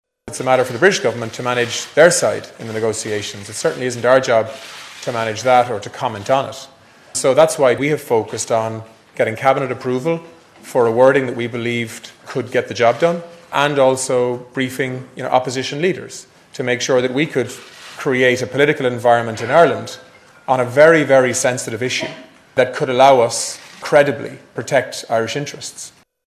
Simon Coveney says it’s now up to Theresa May to negotiate with Arlene Foster: